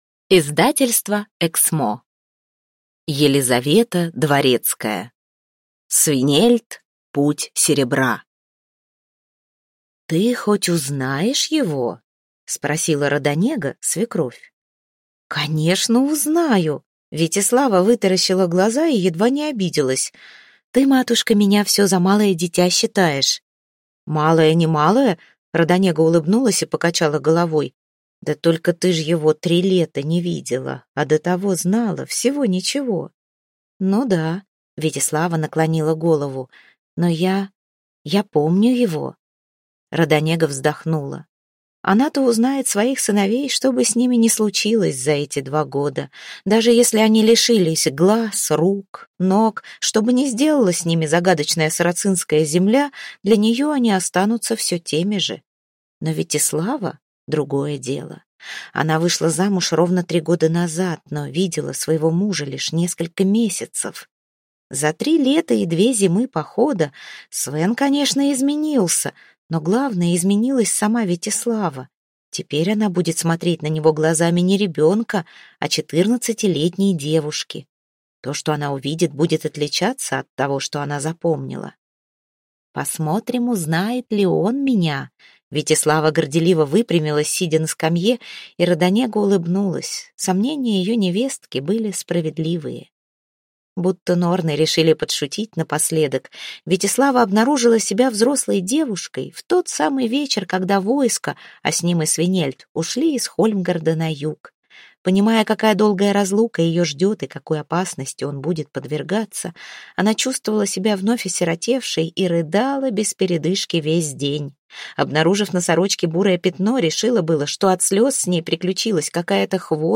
Аудиокнига Свенельд. Путь серебра | Библиотека аудиокниг